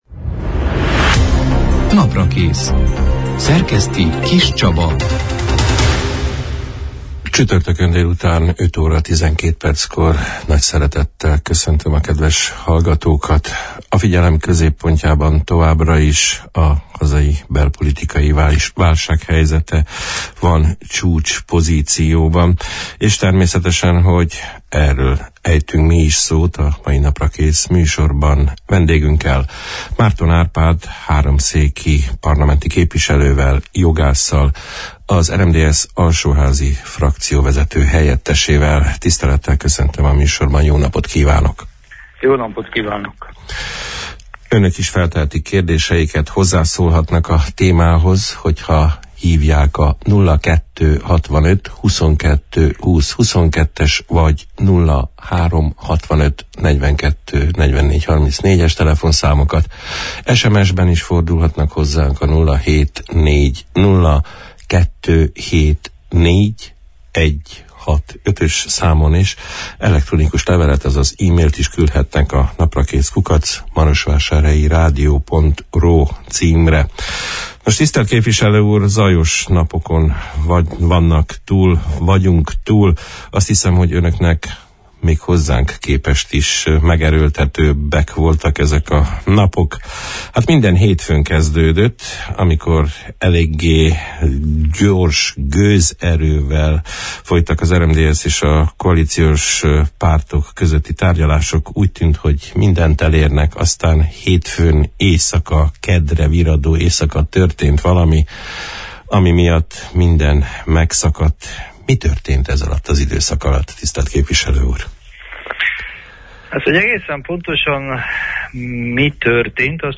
A hét elején lezajlott politikai tárgyalások kimeneteléről, a hirtelen bekövetkezett változás okairól, a szövetség jövő heti tárgyalásokra való előkészületeiről, az alkotmánybíróság újabb döntésének hatásairól, az RMDSZ törekvéseinek napirenden tartási esélyeiről beszélgettünk a június 22 – én, csütörtökön elhangzott Naprakész műsorban Márton Árpád háromszéki jogász politikussal, az RMDSZ alsóházi frakcióvezető helyettesével.